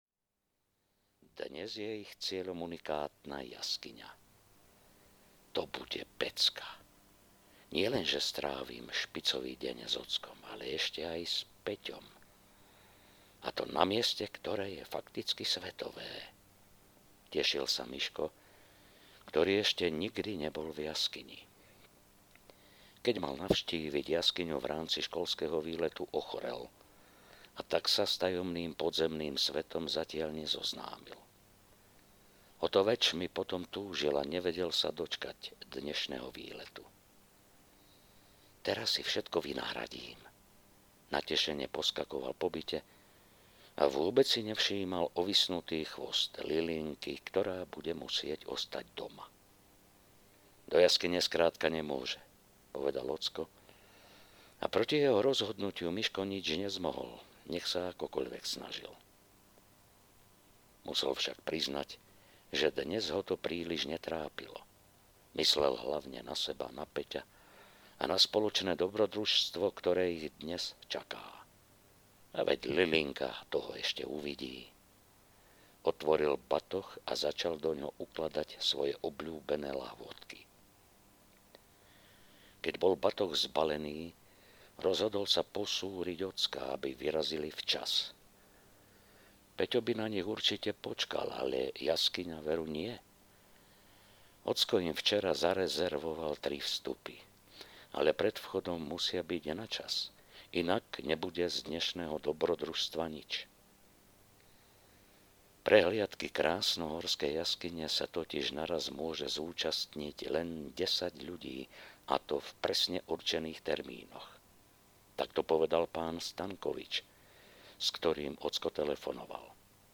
Vodné dobrodružstvá 2 audiokniha
Ukázka z knihy